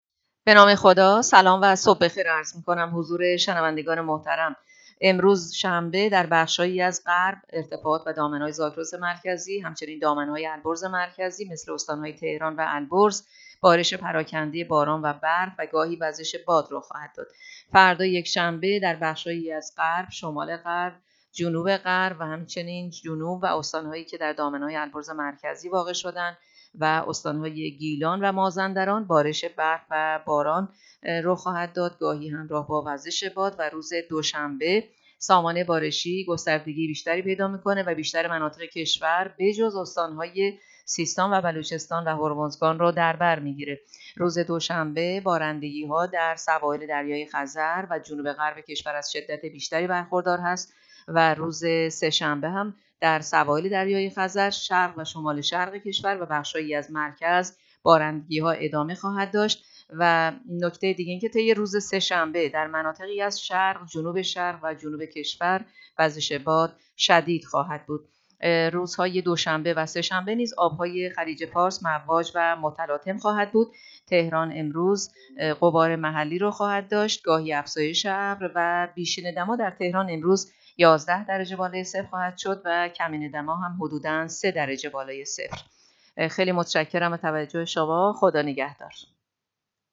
گزارش رادیو اینترنتی پایگاه‌ خبری از آخرین وضعیت آب‌وهوای ۶ بهمن؛